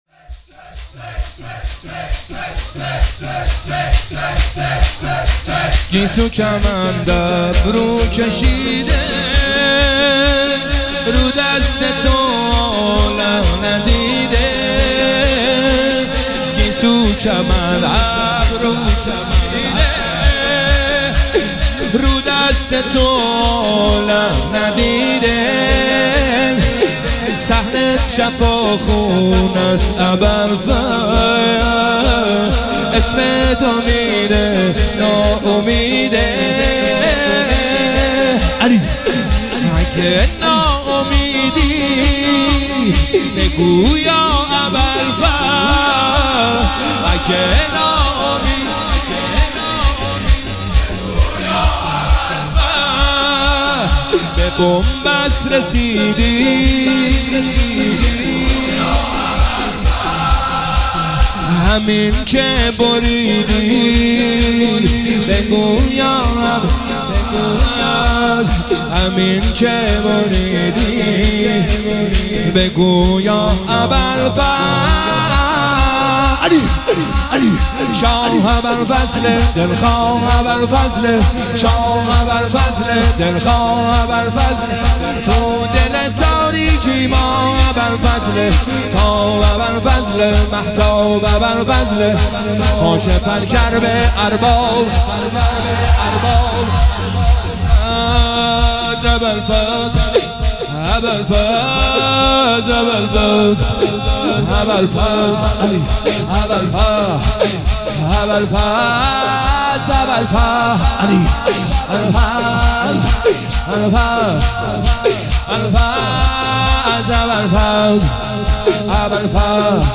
شب 21 ماه رمضان 1446